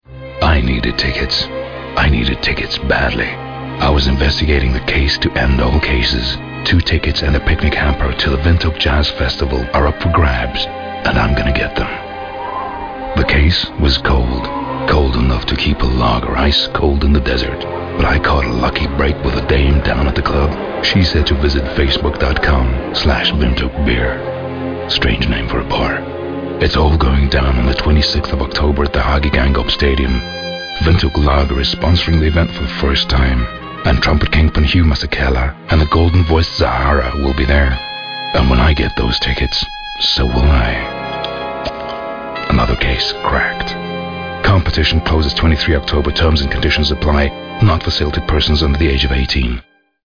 windhoek-jazz-festival-50s.mp3